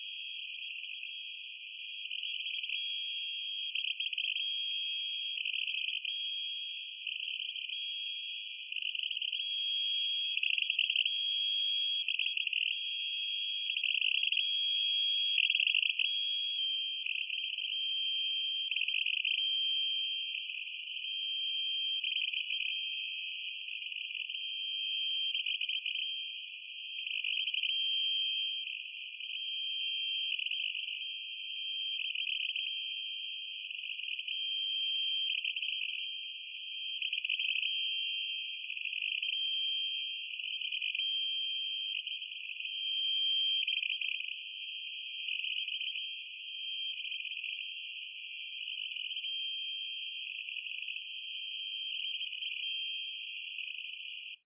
Странный сигнал на фоне жужжалки на частоте 4625 кГц. Очищен от жужжалки.